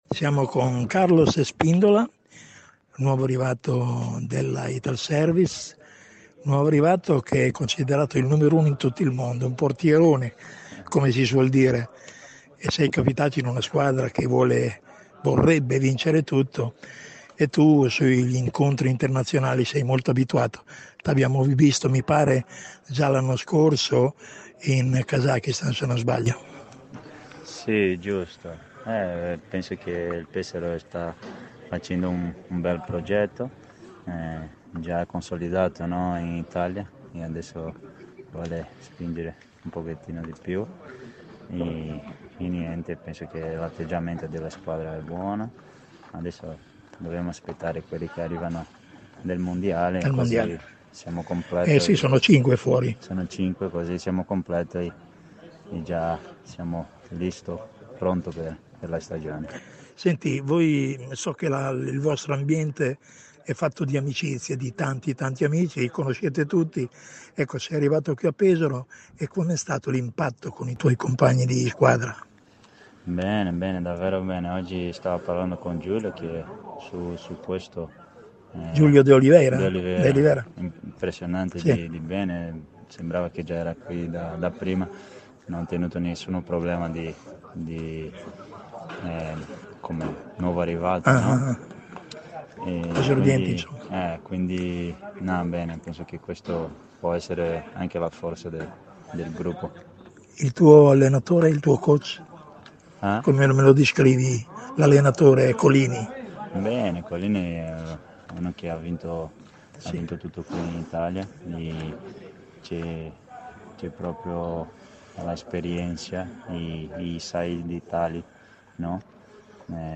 La nostra intervista a fine partita a